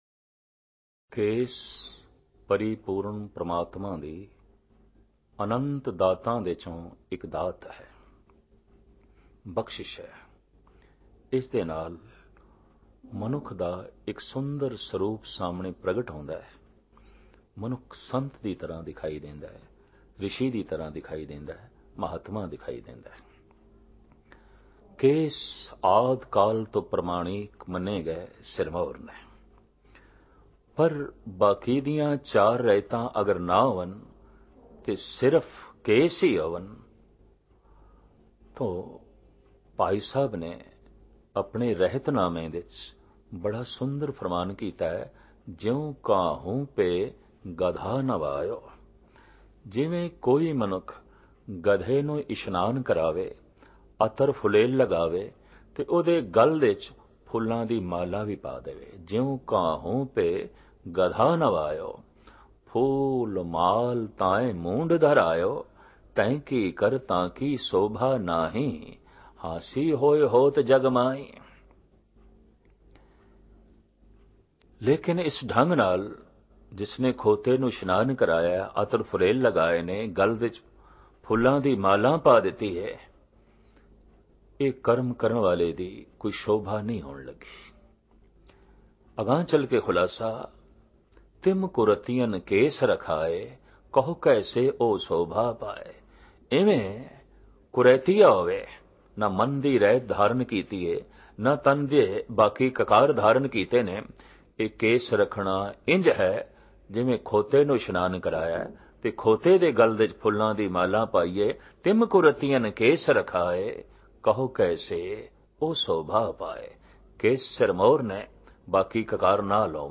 Listen and Download Katha
Album:Rehtan Sikhi Dian Genre: Gurmat Vichar